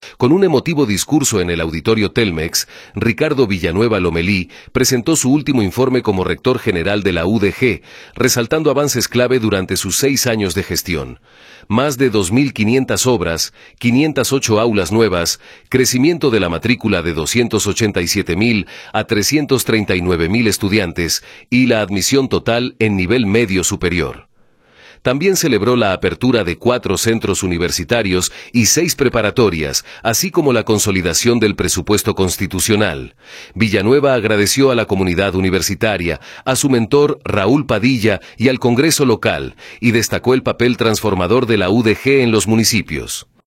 Con un emotivo discurso en el Auditorio Telmex, Ricardo Villanueva Lomelí presentó su último informe como rector general de la UdeG, resaltando avances clave durante sus seis años de gestión: más de 2,500 obras, 508 aulas nuevas, crecimiento de la matrícula de 287 mil a 339 mil estudiantes y la admisión total en nivel medio superior. También celebró la apertura de cuatro centros universitarios y seis preparatorias, así como la consolidación del presupuesto constitucional.